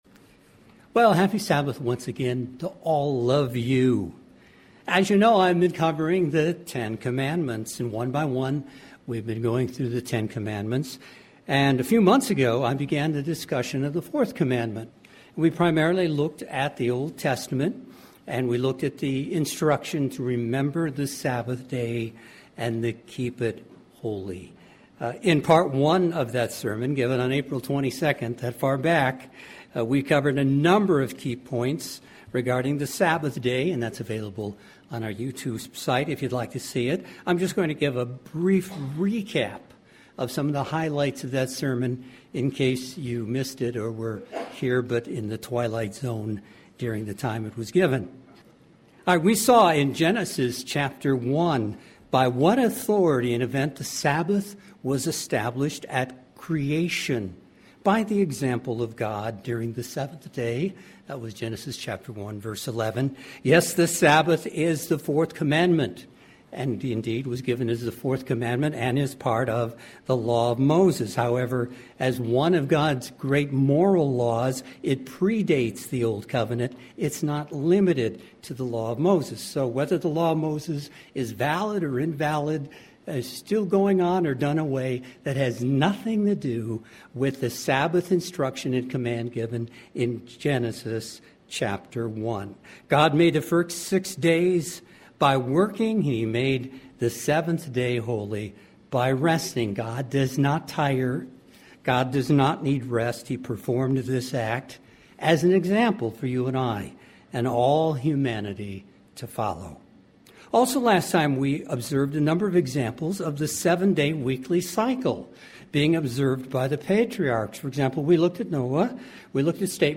In part 1 of that Sermon given on April 22nd, we covered a number of key points regarding the Sabbath Day. Today, we will look at Scriptures written by the Apostle Paul that are typically “twisted”… or taken out of context in an attempt to show that the 7th day Sabbath is now obsolete or changed to another day.